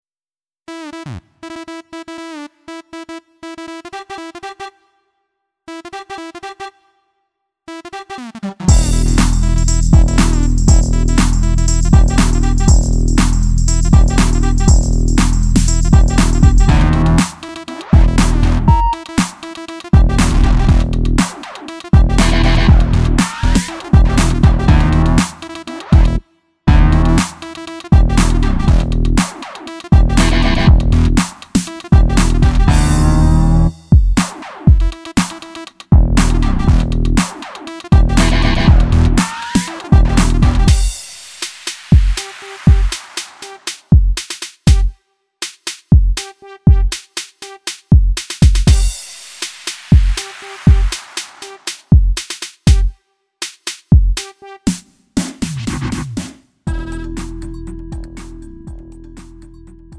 음정 -1키 2:53
장르 가요 구분